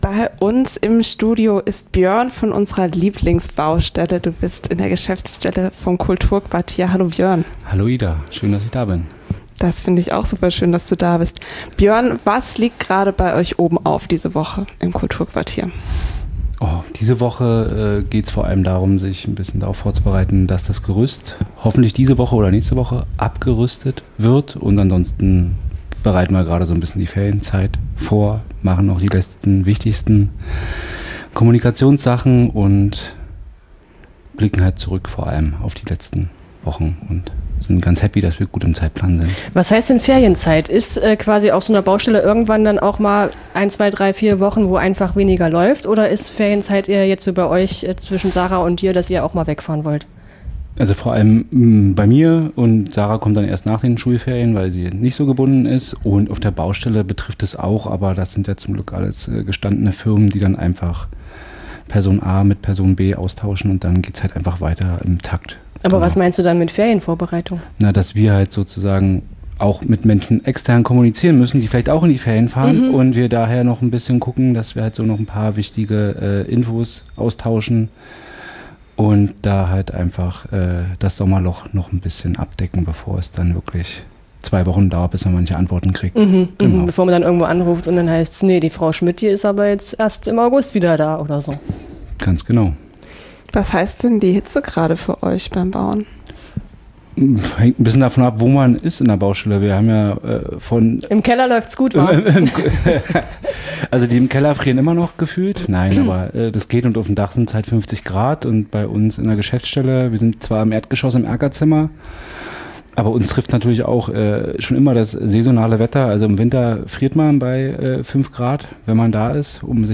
In der Baustellenradiosendung "Baggertelle" sprechen wir monatlich über das, was gerade geschieht.